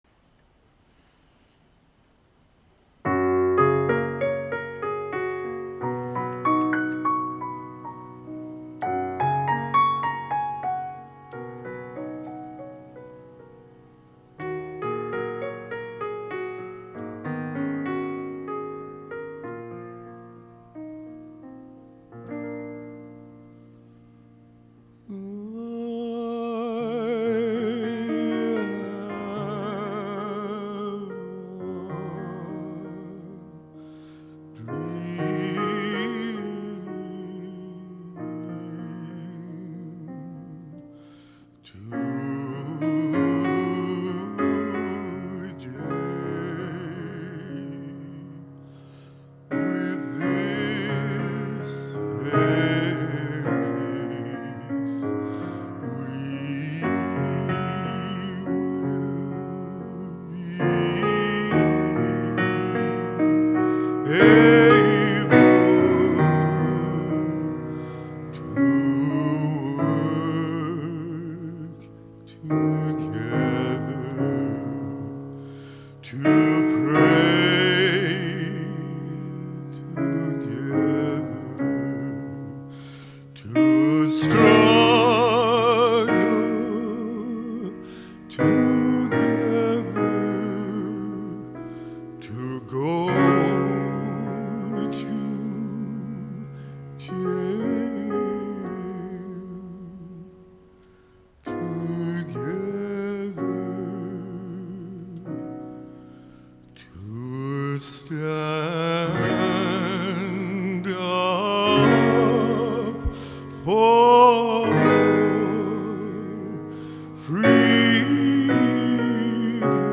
a new arrangement of the song premiered at the Sri Chinmoy Poetry Festival
piano